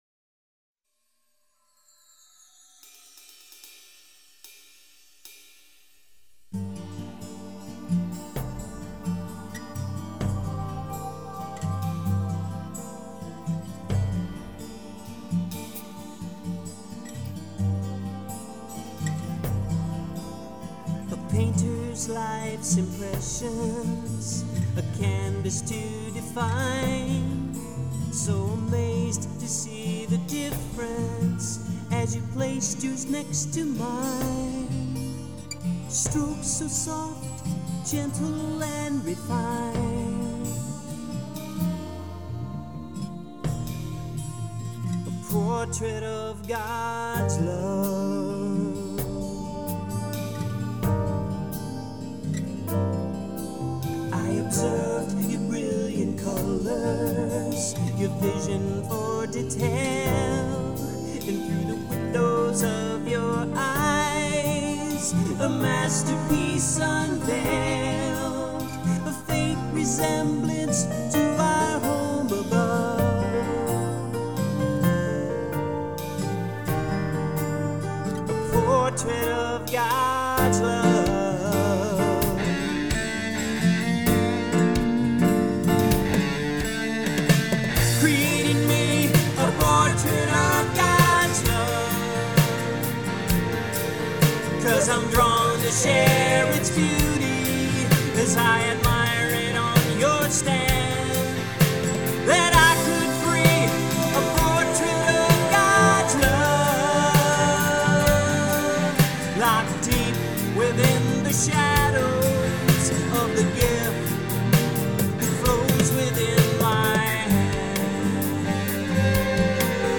Acoustic Guitars, Keyboards,drum programming, vocals
Electric Guitars
Lead vocals